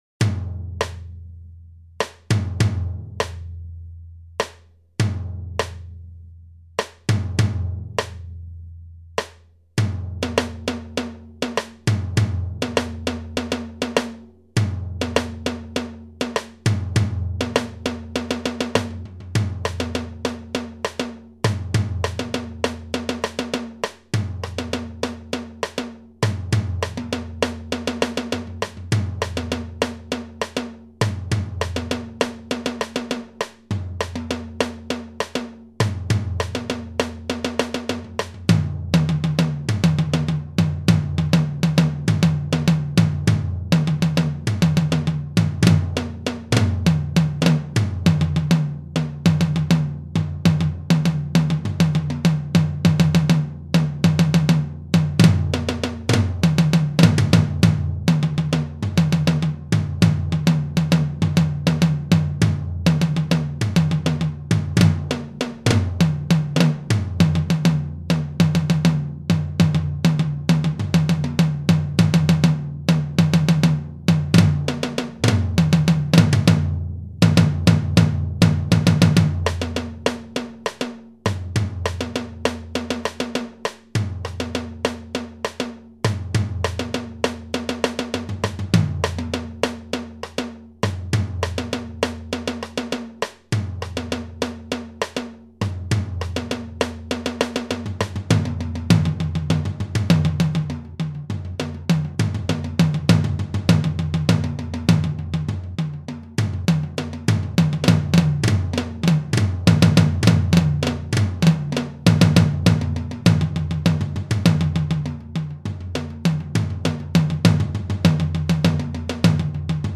Muziekvorm Concert